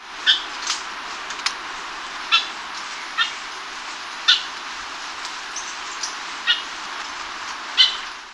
St. Lucia Oriole
Icterus laudabilis